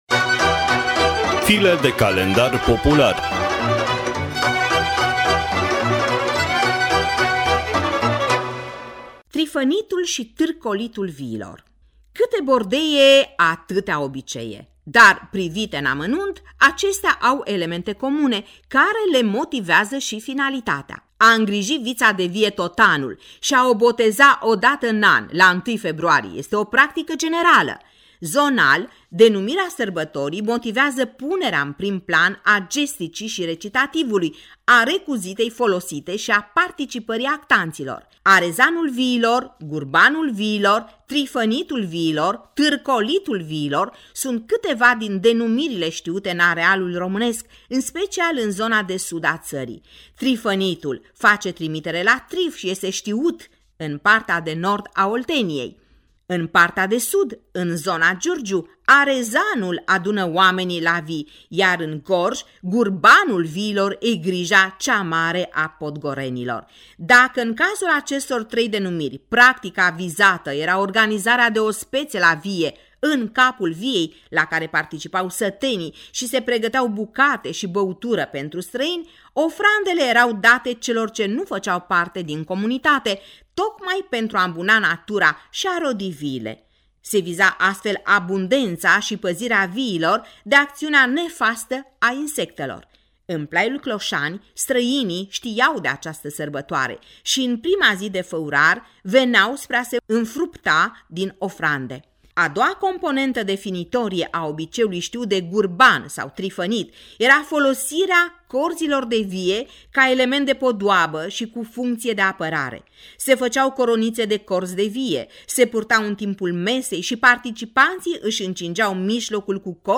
Text și lectură